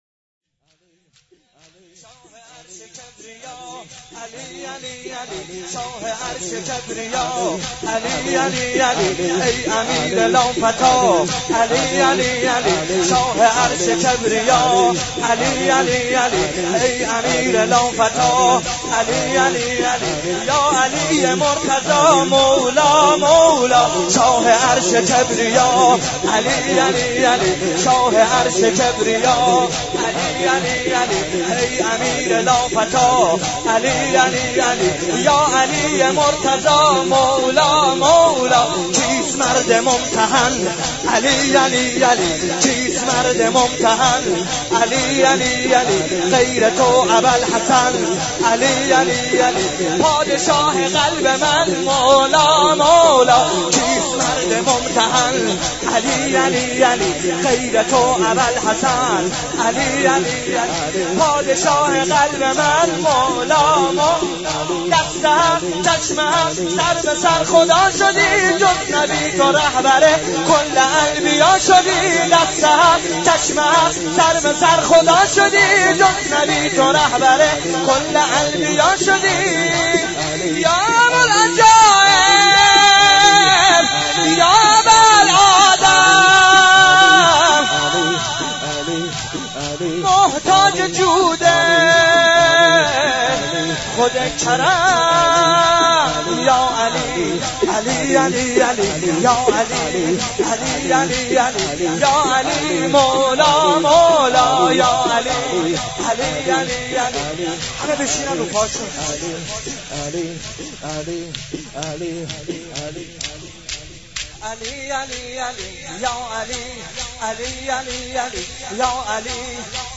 مراسم شب بیستم ماه مبارک رمضان با مداحی